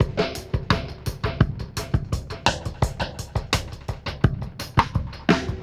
Index of /musicradar/dub-drums-samples/85bpm
Db_DrumsA_KitEcho_85_03.wav